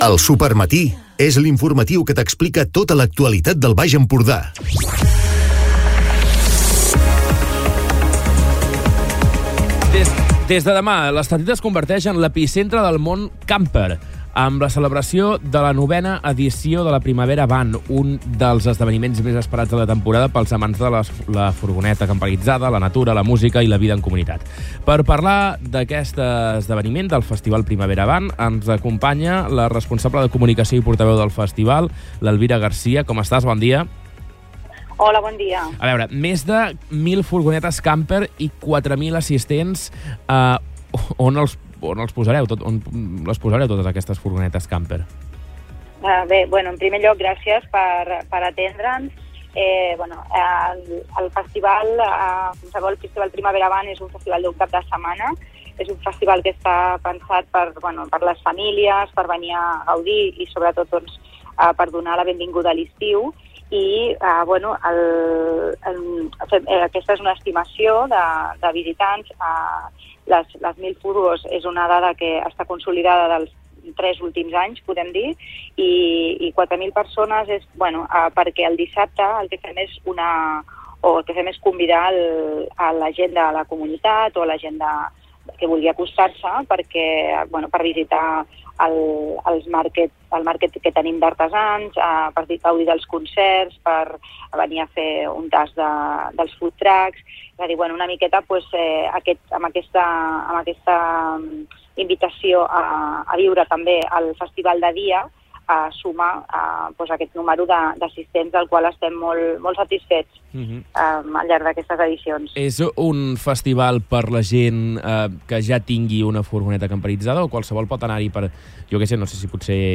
Entrevistes